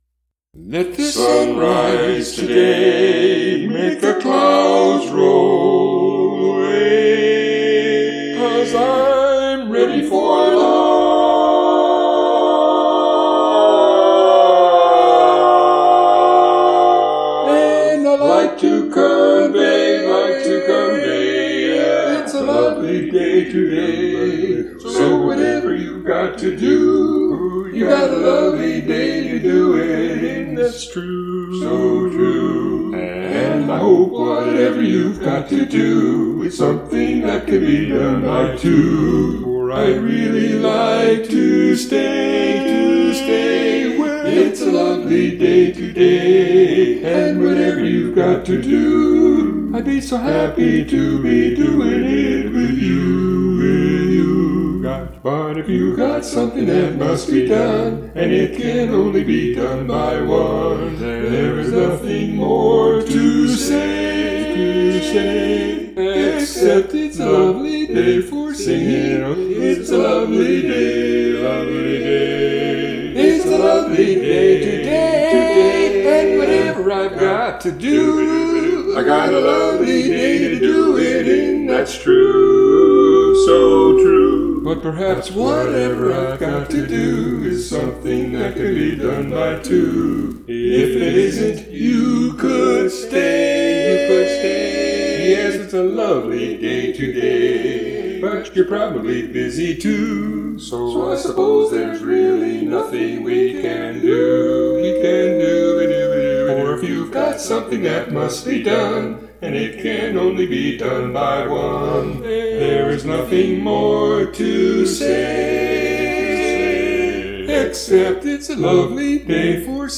I love to sing in Barbershop and Acappella style.